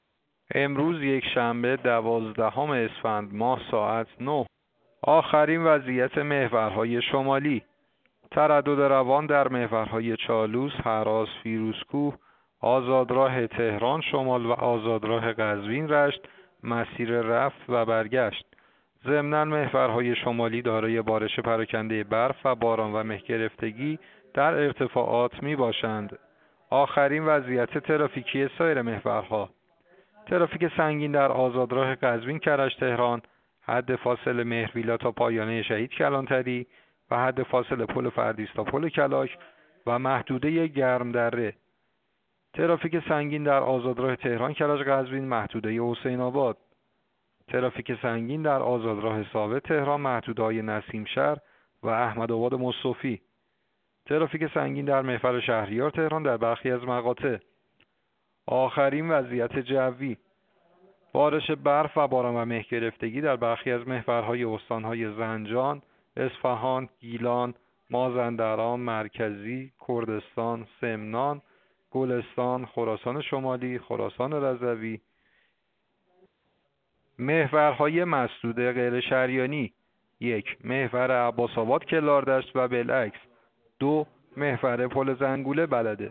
گزارش رادیو اینترنتی از آخرین وضعیت ترافیکی جاده‌ها ساعت ۹ دوازدهم اسفند؛